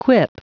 Prononciation du mot quip en anglais (fichier audio)